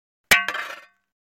恐怖类型的运动 " 抨击铁2
Tag: 金属 金属 踩住